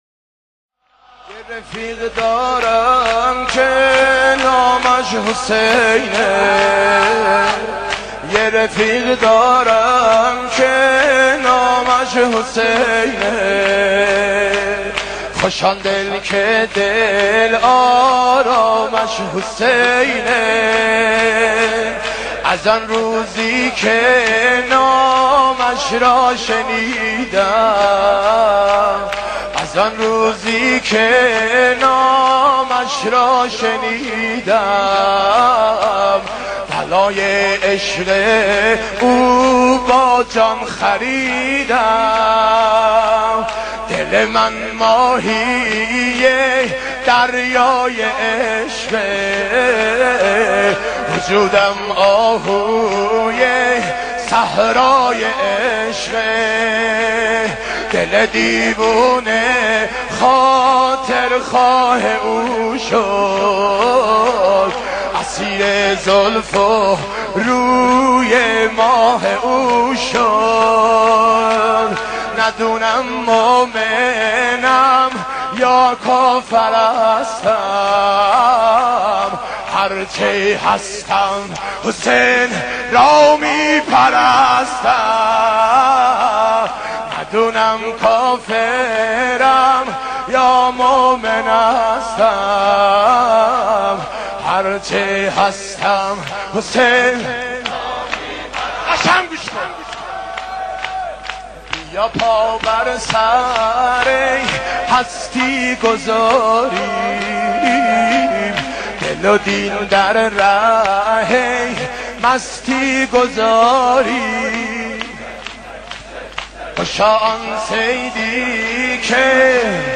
مداحی های ویژه عروج مرحوم سیدجواد ذاکر
عقیق:مداحی های زیبای مرحوم سیدجواد ذاکر